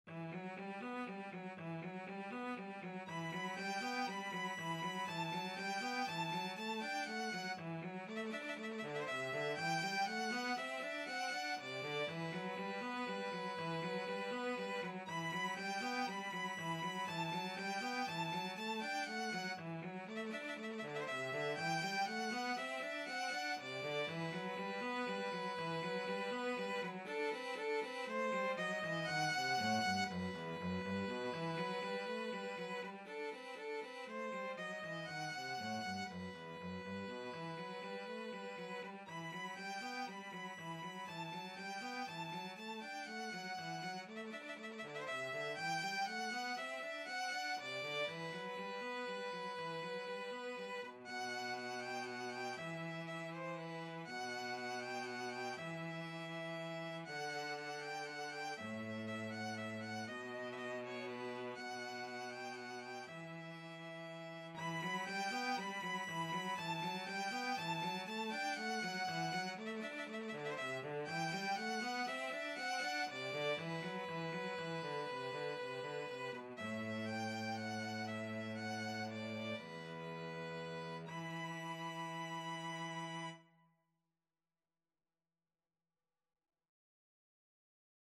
Free Sheet music for Violin-Cello Duet
E minor (Sounding Pitch) (View more E minor Music for Violin-Cello Duet )
3/4 (View more 3/4 Music)
Andante (one in a bar) = 120
Classical (View more Classical Violin-Cello Duet Music)